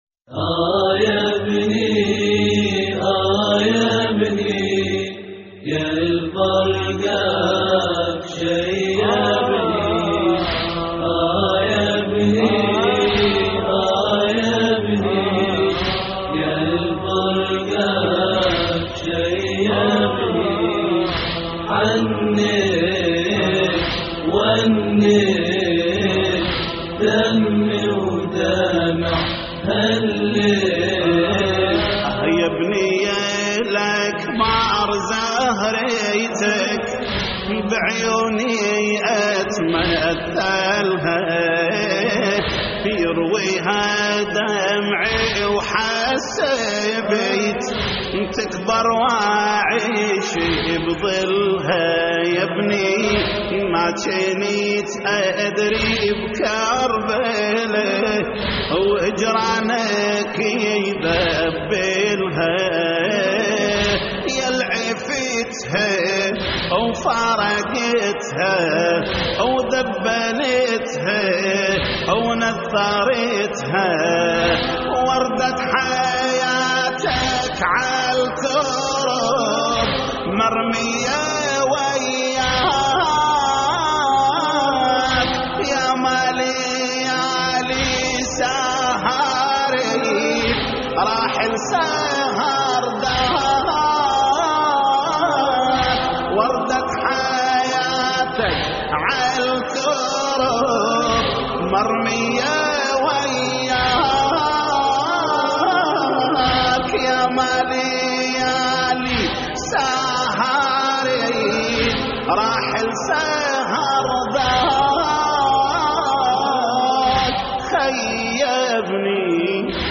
تحميل : اه يا ابني يالفرقاك شيبني حنّيت ونّيت / الرادود جليل الكربلائي / اللطميات الحسينية / موقع يا حسين